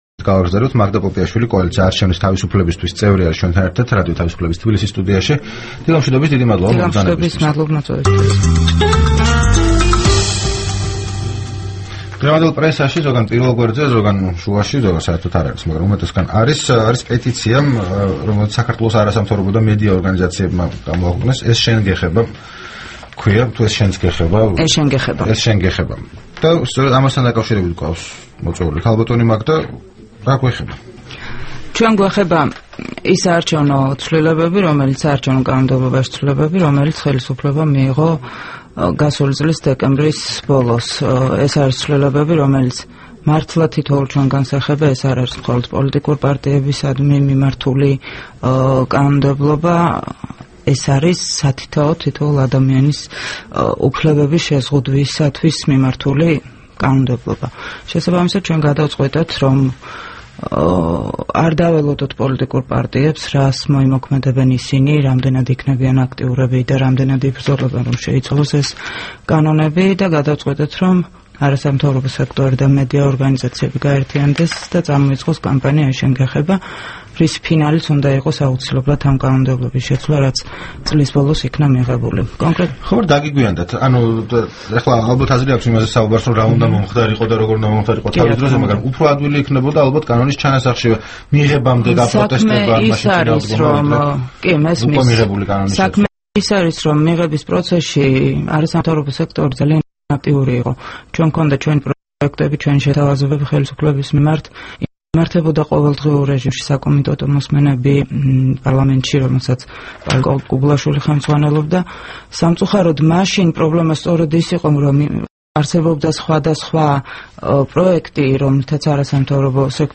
სტუმრად ჩვენს ეთერში